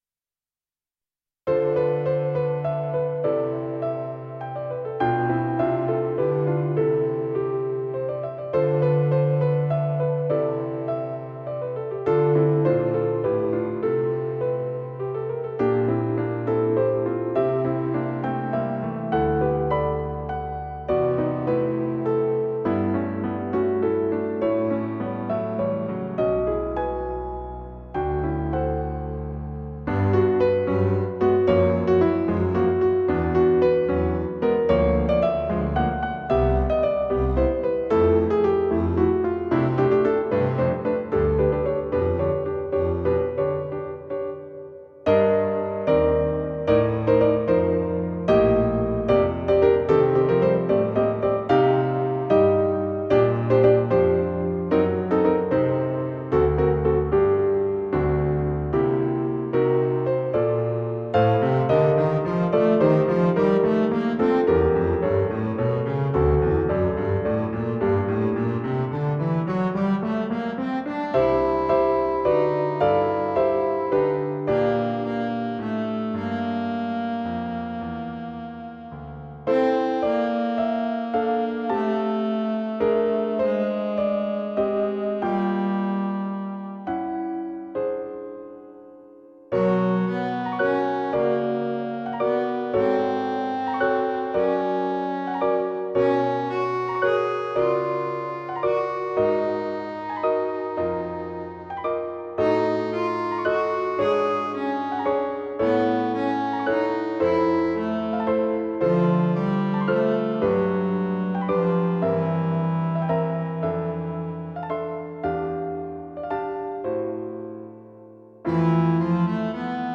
クラシック 　ＭＩＤＩ(25KB） 　YouTube
ＭＰ３(3.1MB） ピアノとコントラバスが、小さな黄金風景を奏でます。